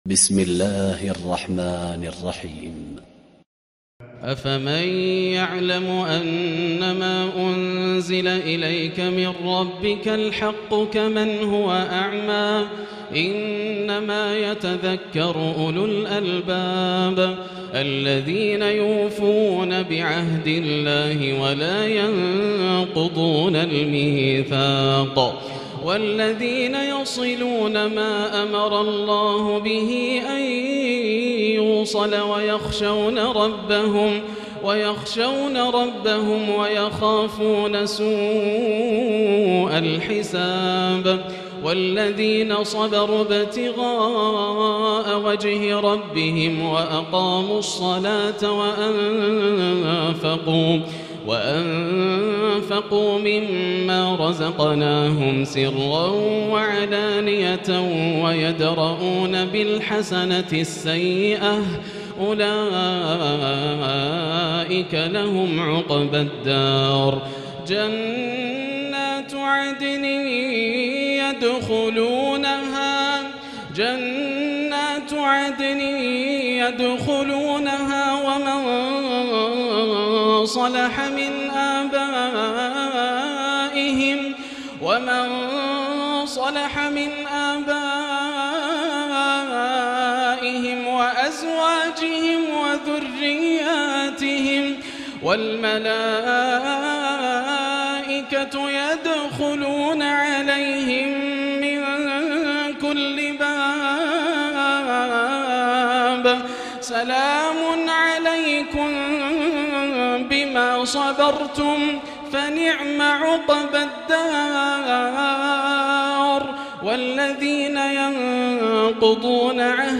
الليلة الثانية عشرة - ما تيسر من سورة الرعد 19 حتى نهاية سورة إبراهيم > الليالي الكاملة > رمضان 1438هـ > التراويح - تلاوات ياسر الدوسري